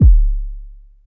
07_Kick_07_SP.wav